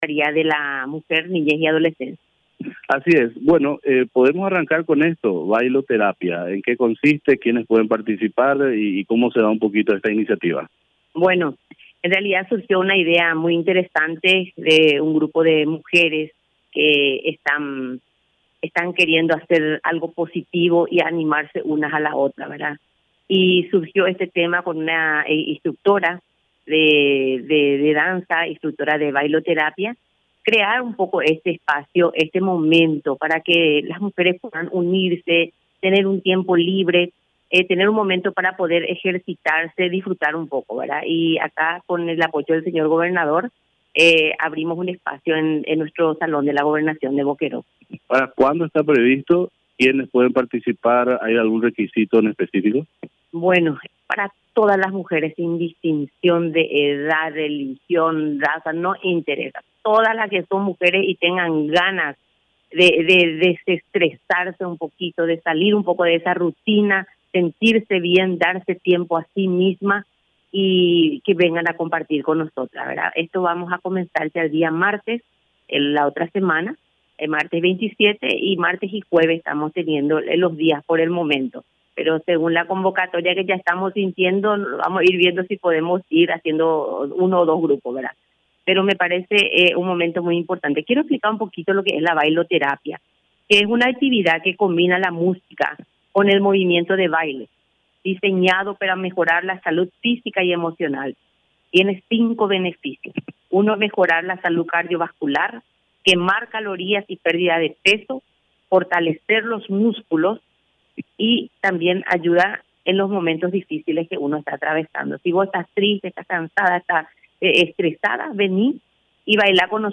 Entrevistas / Matinal 610
Entrevistado: Lic. Sonia Samudio
Estudio Central, Filadelfia, Dep. Boquerón